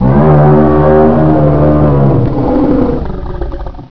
sound / minotaur / death.wav